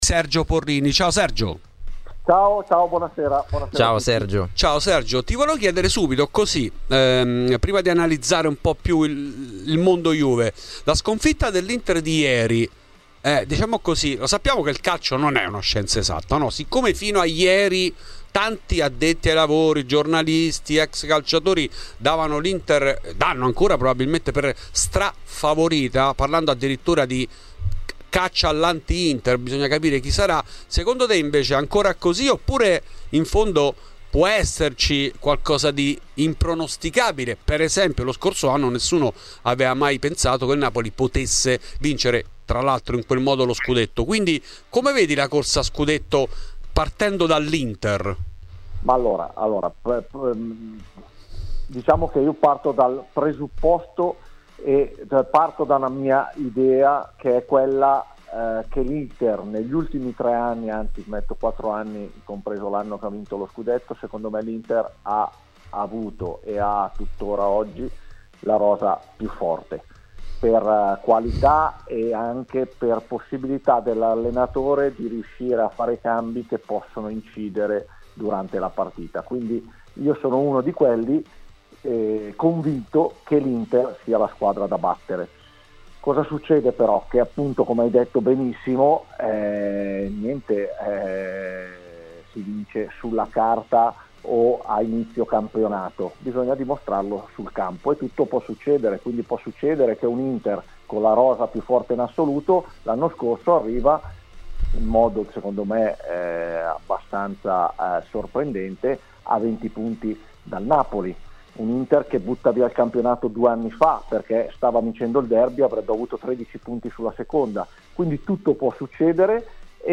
Questi argomenti sono contenuti nell'intervento in ESCLUSIVA a Fuori di Juve di Sergio Porrini , L'ex difensore bianconero e della Dea ha parlato anche di Gatti, Bremer, Bonucci, Pogba e della sfida di domenica a Bergamo.